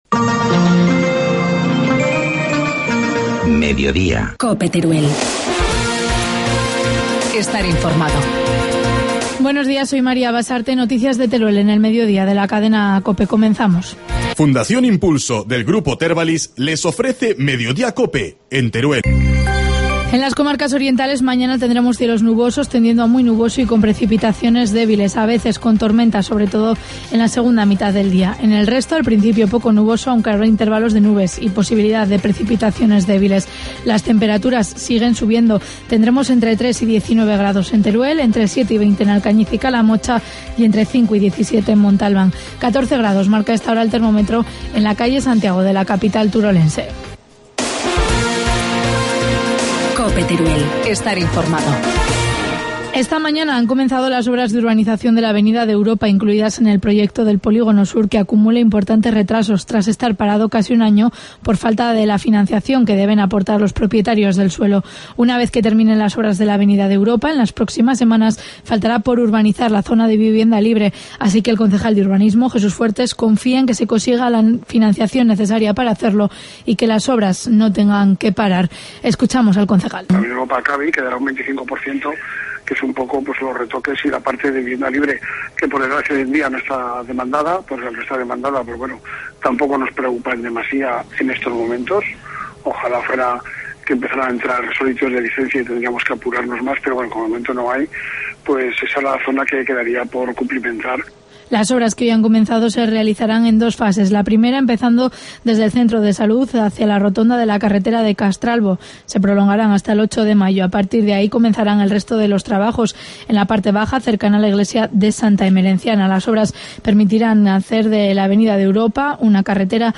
Informativo mediodía, miércoles 24 de abril